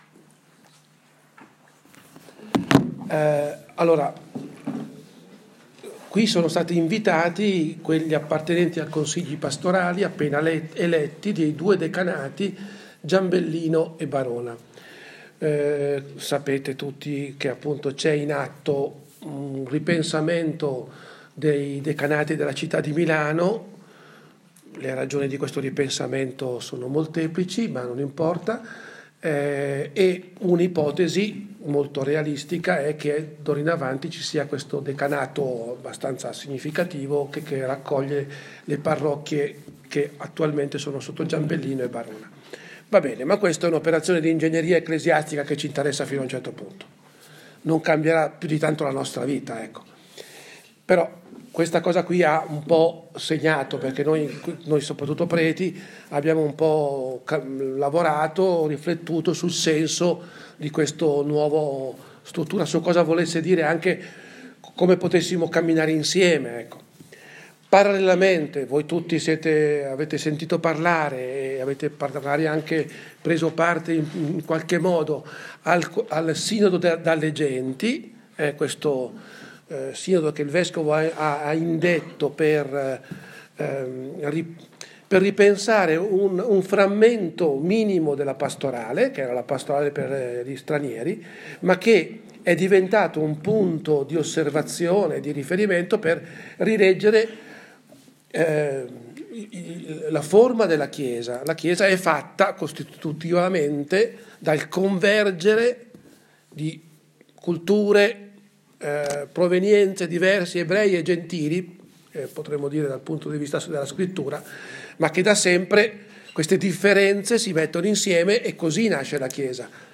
Registrazione dell'incontro